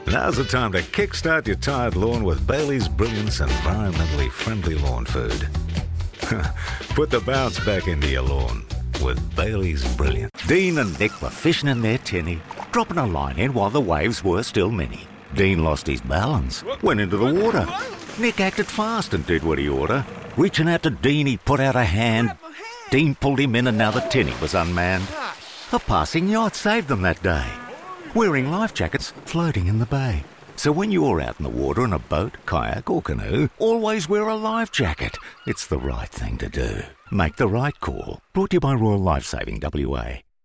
He has a huge range of character voices and a wide range of accents suitable for gaming and animation.
• Male
• Australian